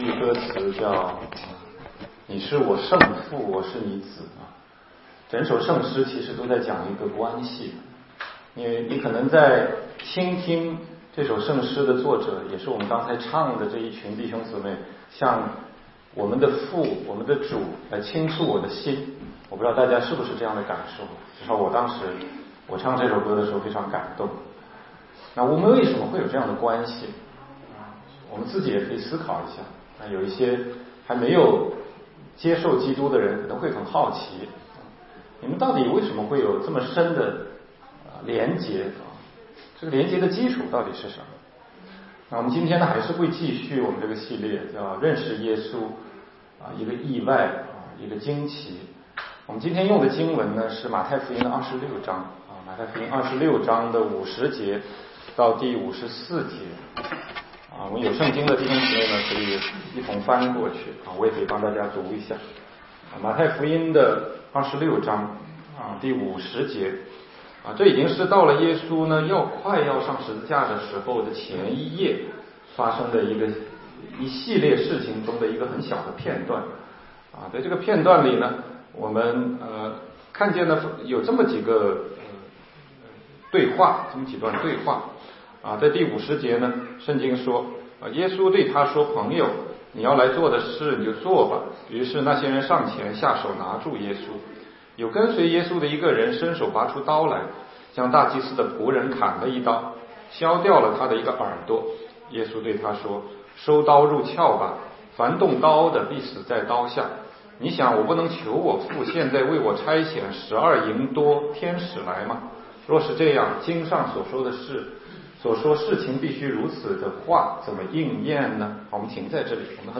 16街讲道录音 - 出人意料的耶稣—耶稣被捕
全中文查经